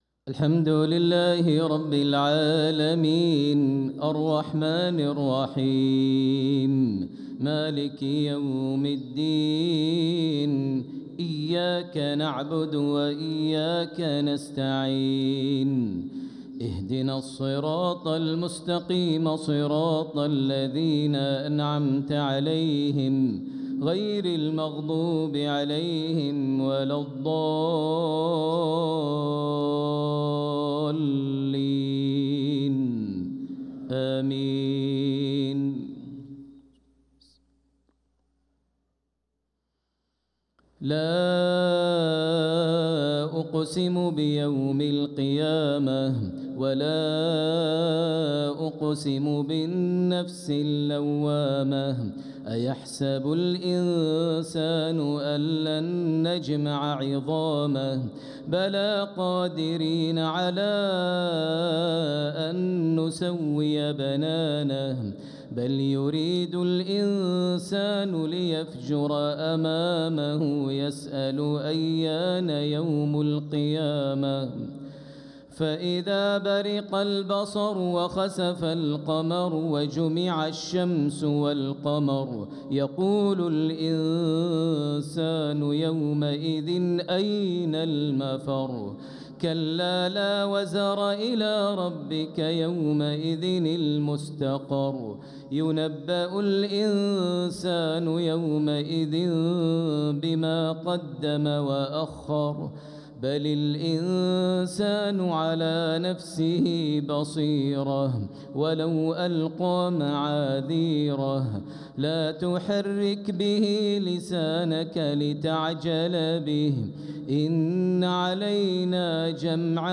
صلاة المغرب للقارئ ماهر المعيقلي 14 صفر 1446 هـ